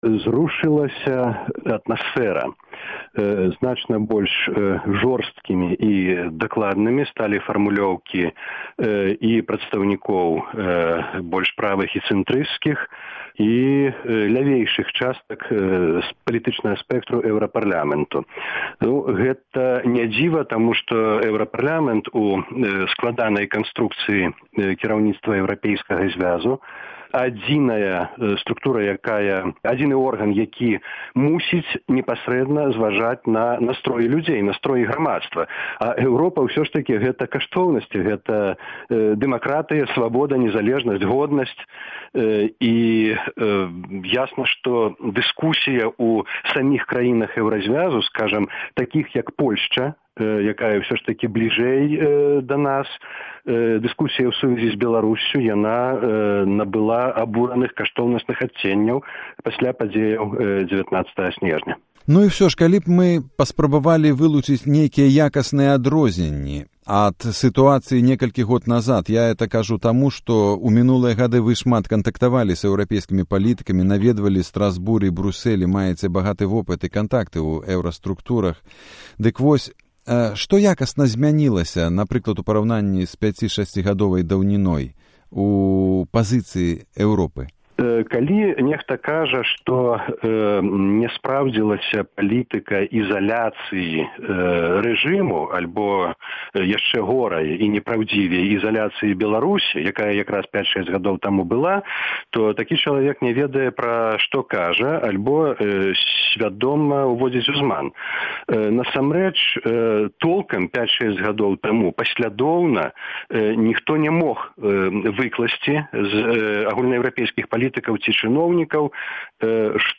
Госьць ранішняга эфіру – былы старшыня БНФ Вінцук Вячорка, які ў сераду сачыў за абмеркаваньнем беларускага пытаньня ў Эўрапарлямэнце.
Гутарка зь Вінцуком Вячоркам. 20.01.2011